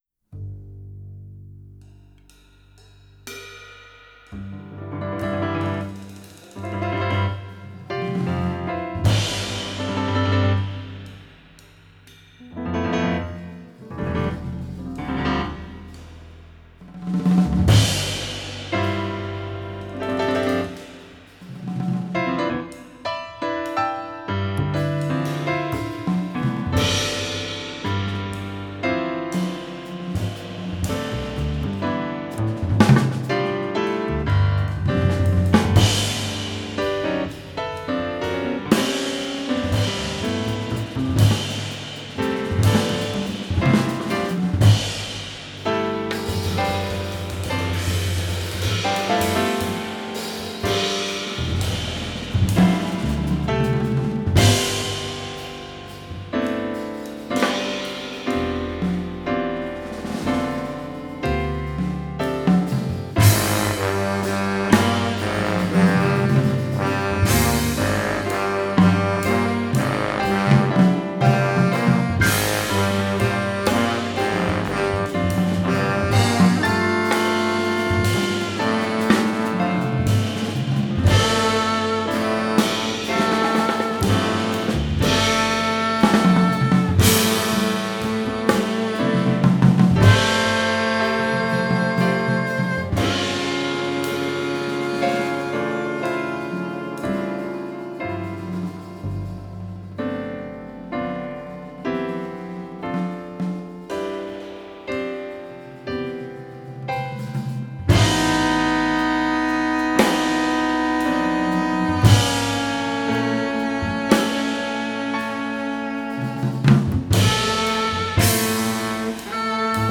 Reeds
Trombone
Piano
Bass
Drums